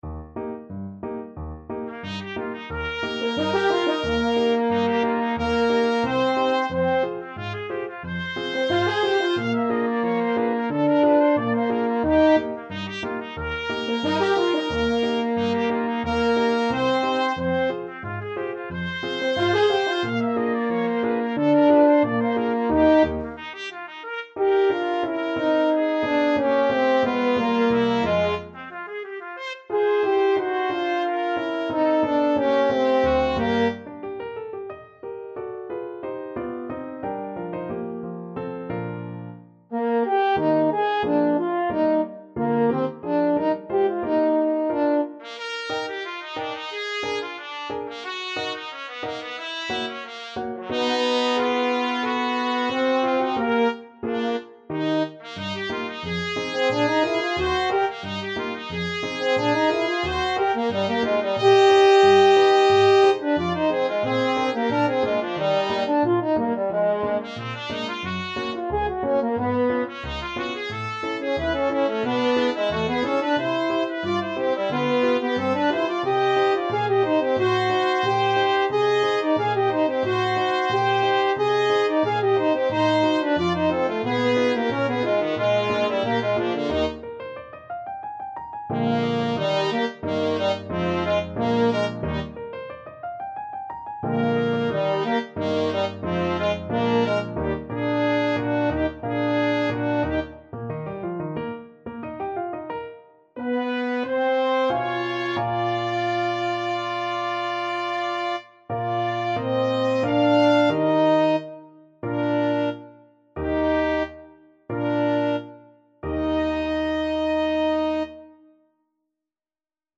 (with piano)
4/4 (View more 4/4 Music)
Allegro Moderato [ = c.90] (View more music marked Allegro)